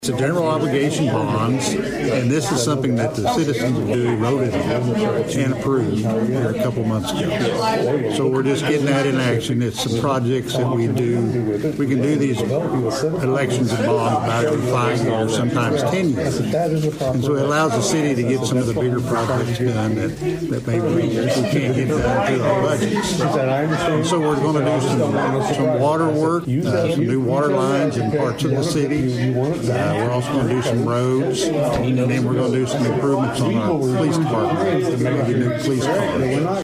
Mayor Tom Hays spoke on the bonds.